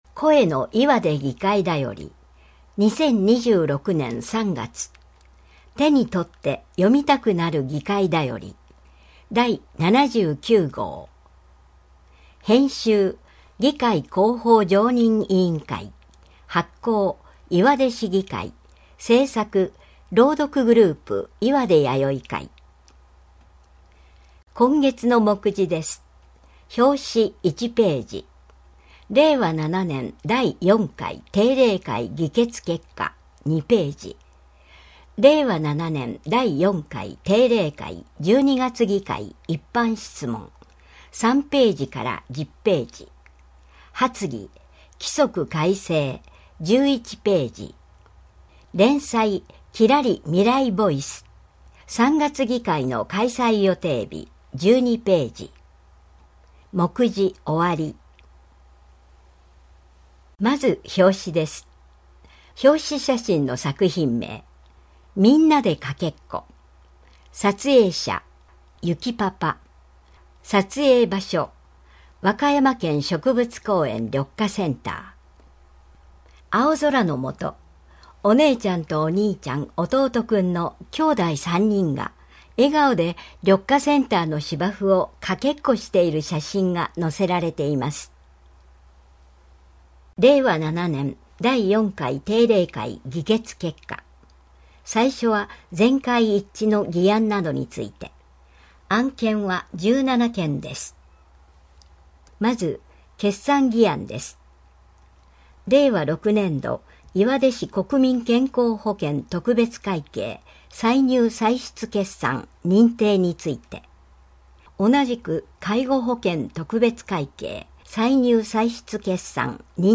視覚に障害のある方や高齢の方にも市議会の動きなどを知っていただくために、朗読グループ「岩出やよい会」のご協力により、音声データを公開しています。